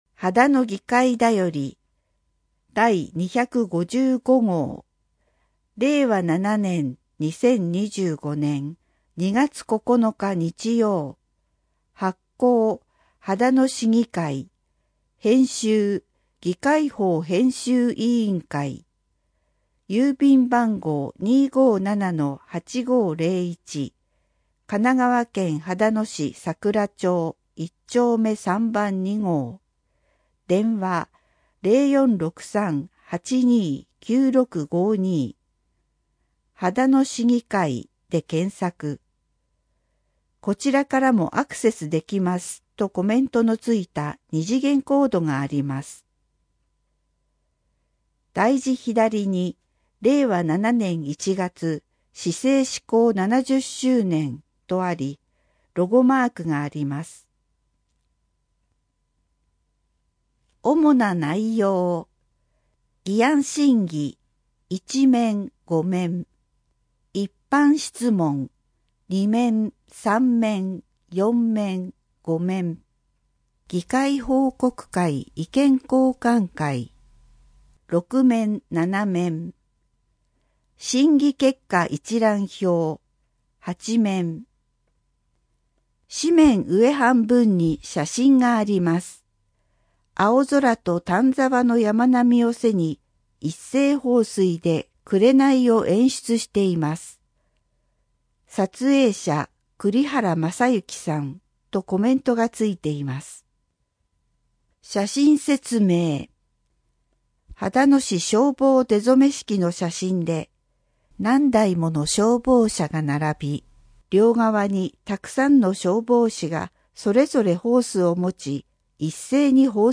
声の議会だより